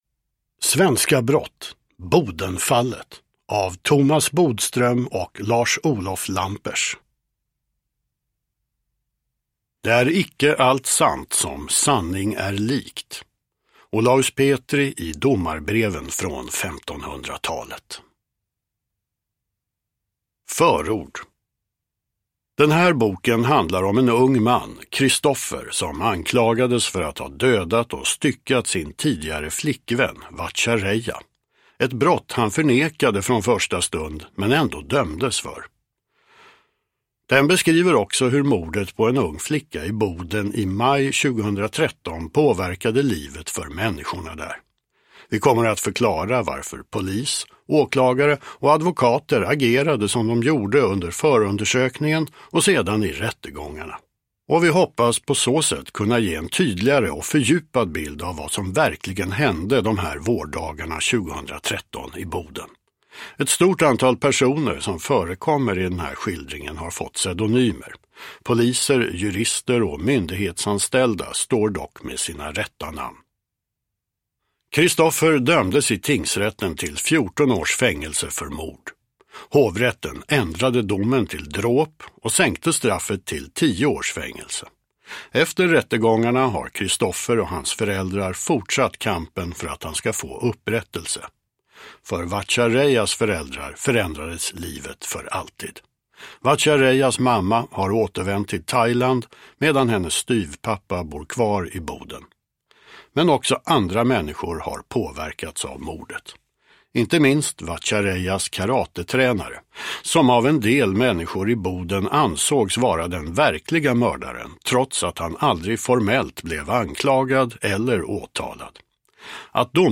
Bodenfallet – Ljudbok – Laddas ner